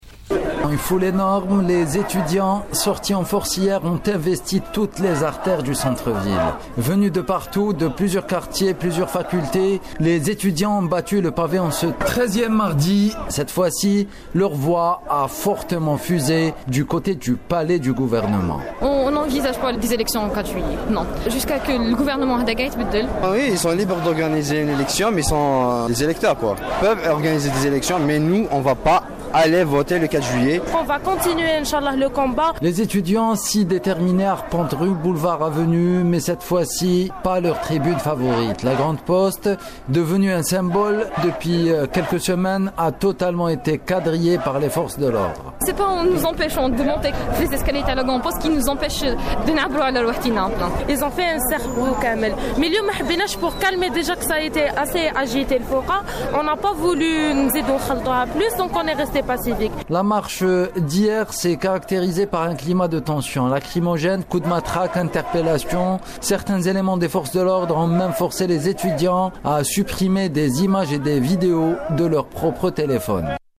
Compte rendu depuis la Place Audin